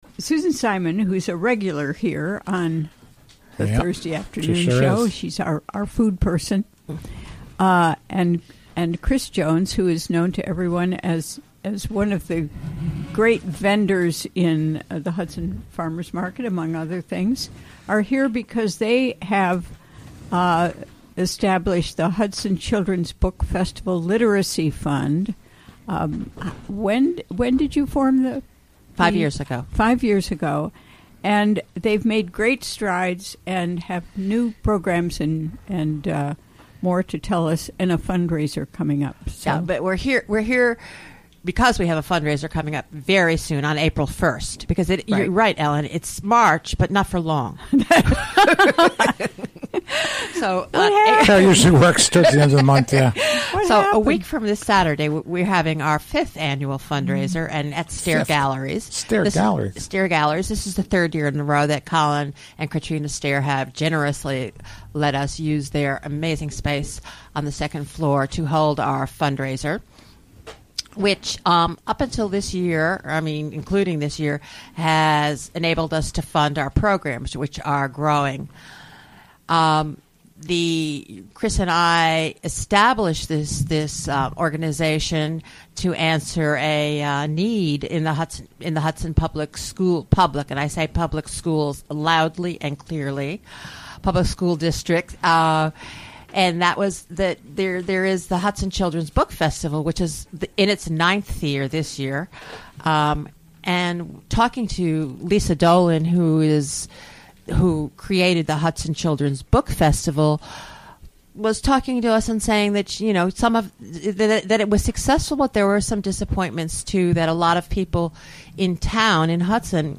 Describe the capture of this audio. Recorded during the WGXC Afternoon Show Thursday, March 23, 2017.